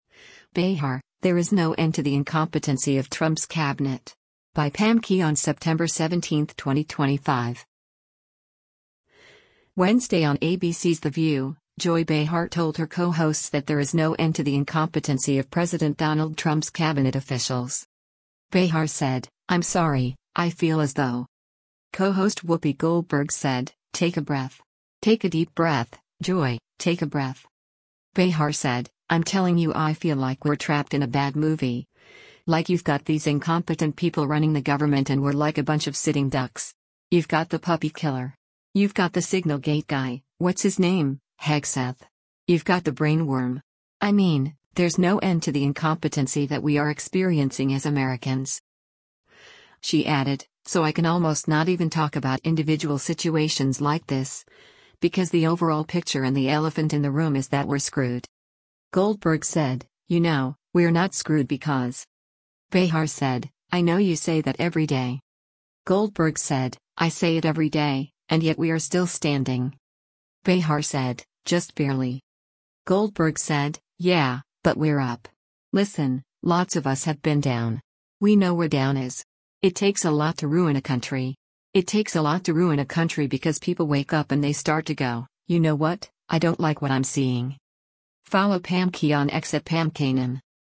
Wednesday on ABC’s “The View,” Joy Behar told her co-hosts that there is “no end to the incompetency of President Donald Trump’s cabinet officials.